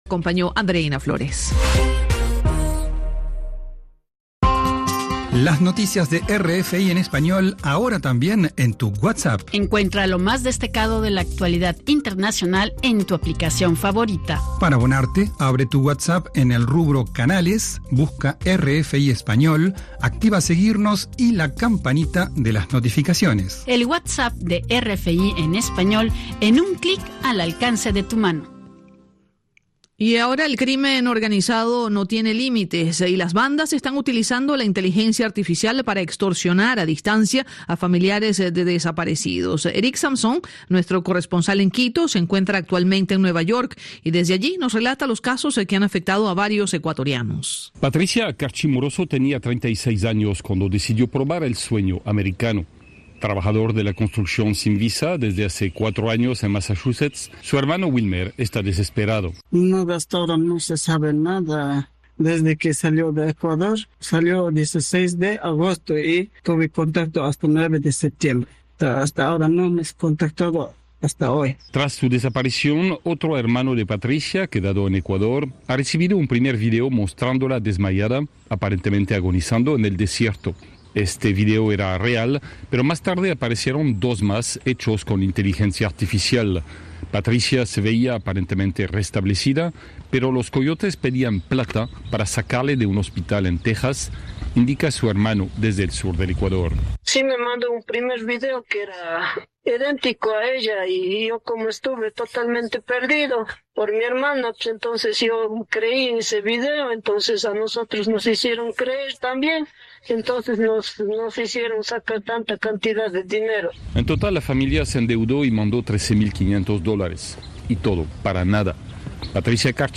Noticieros